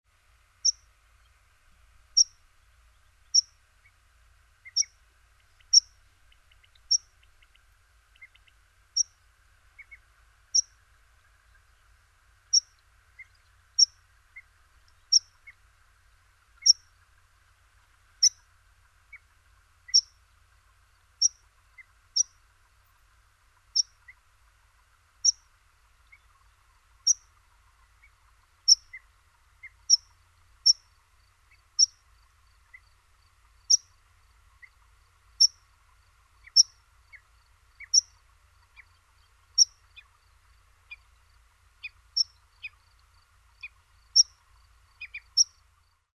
Pispola
(Anthus pratensis)
Pispola-Anthus-pratensis.mp3